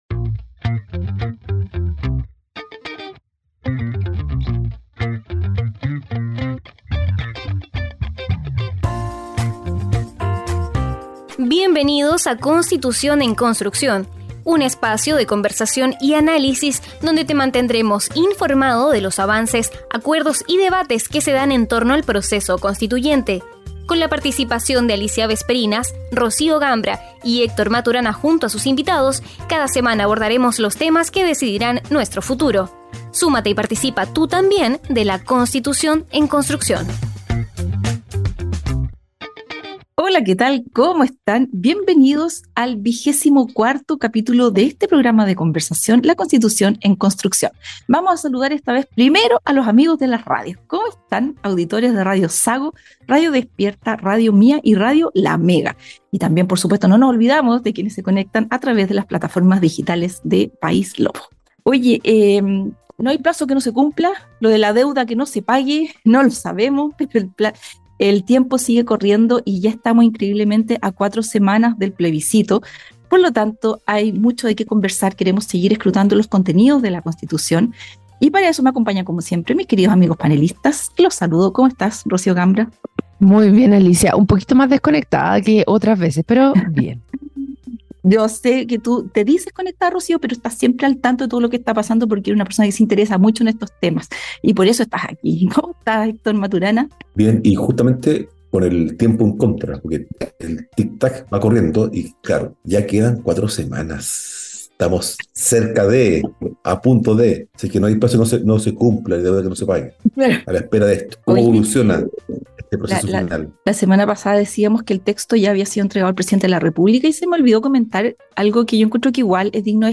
Durante el programa, los panelistas discutieron sobre el Estado social y democrático de derecho en la Constitución, la definición de igualdad de oportunidades y la provisión mixta de servicios sociales a través de instituciones estatales y privadas.